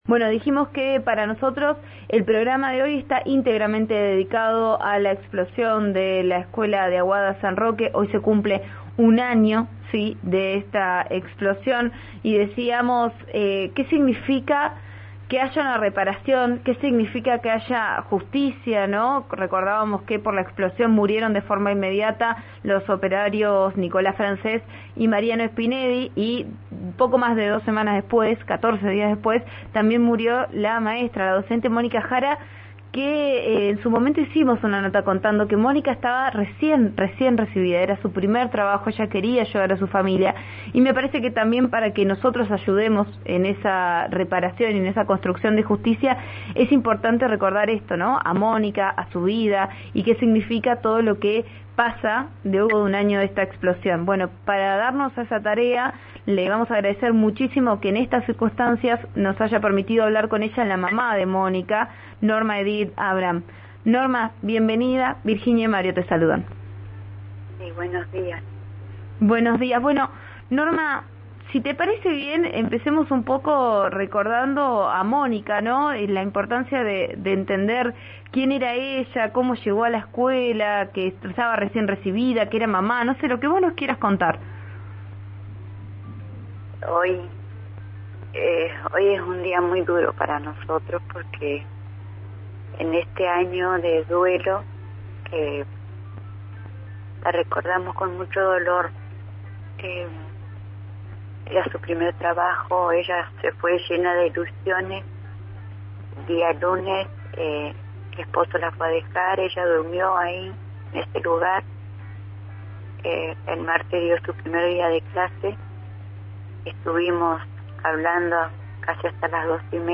Al contrario, tienen ganas de callarnos y que no salgamos a la calle”, sostuvo en diálogo con Vos A Diario, por RN Radio.